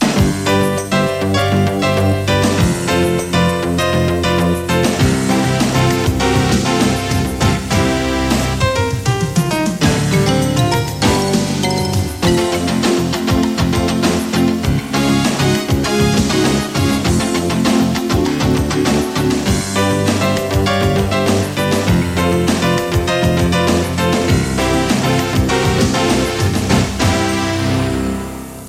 Indicatiu instrumental